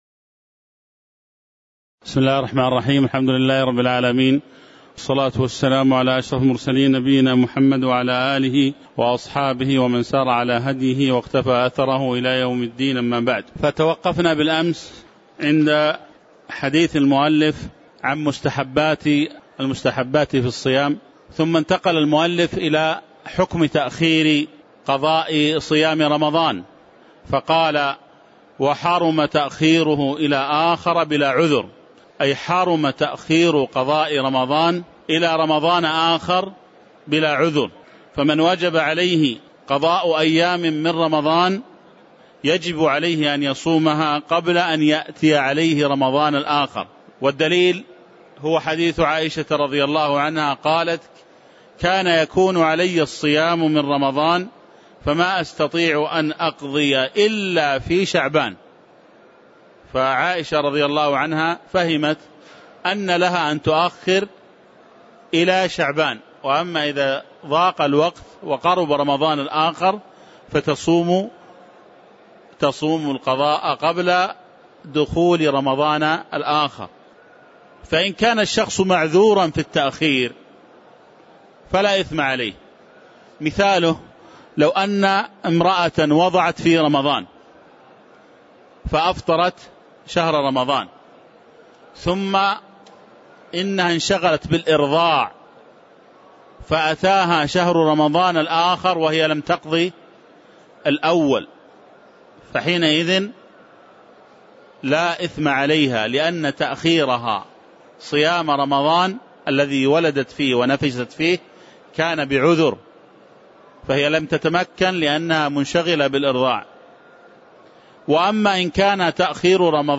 تاريخ النشر ٩ شعبان ١٤٣٩ هـ المكان: المسجد النبوي الشيخ